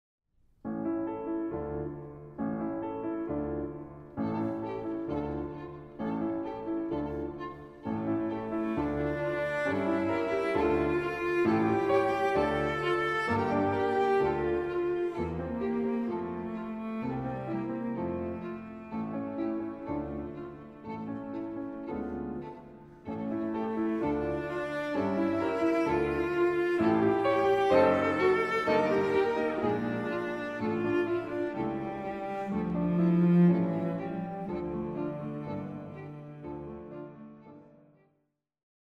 Piano Trio in E minor